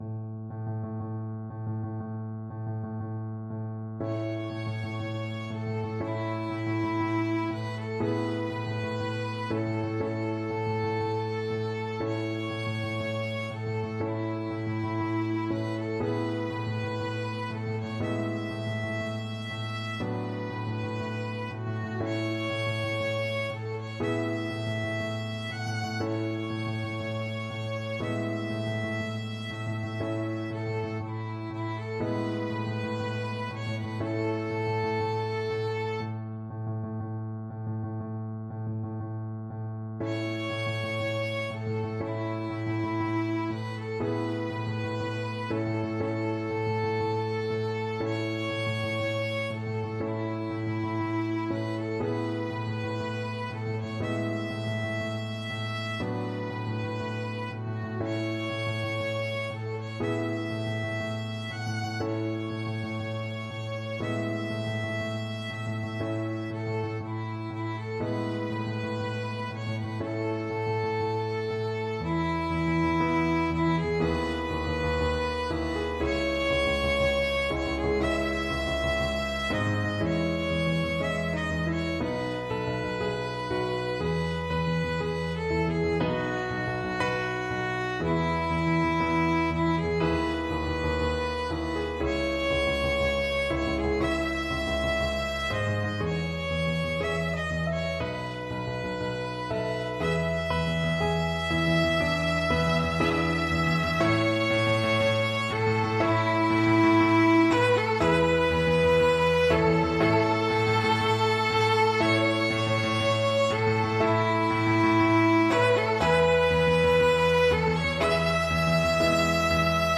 Violin
4/4 (View more 4/4 Music)
Nobilmente = c. 60
Arrangement for Violin and Piano
A major (Sounding Pitch) (View more A major Music for Violin )
Traditional (View more Traditional Violin Music)